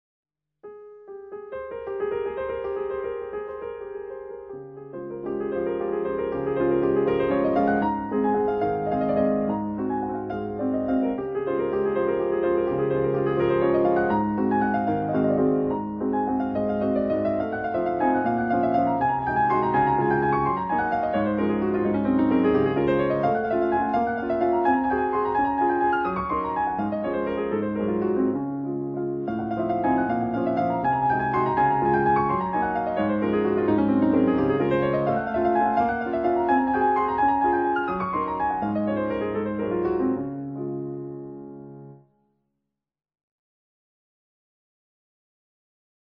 classical piano album